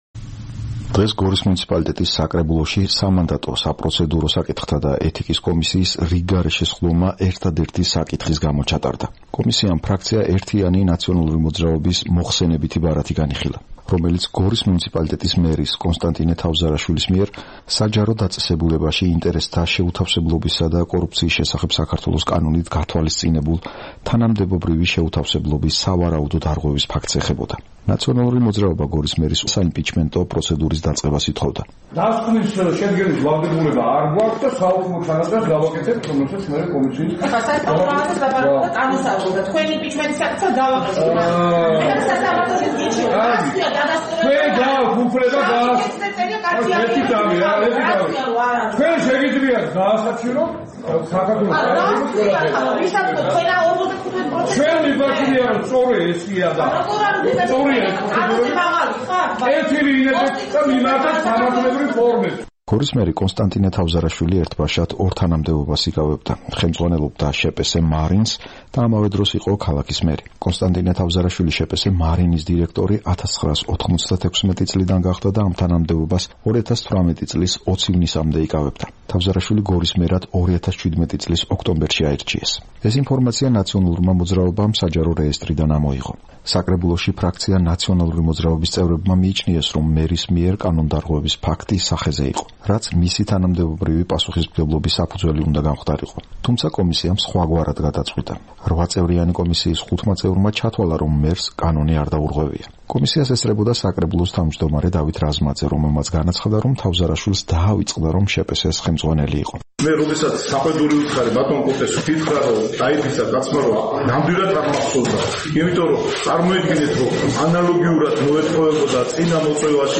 „ნაციონალური მოძრაობა“ გორის მერის მიმართ უნდობლობის გამოცხადებას და საიმპიჩმენტო პროცედურის დაწყებას ითხოვდა. კომისიის სხდომა ხმაურის ფონზე წარიმართა.